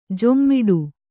♪ jommiḍu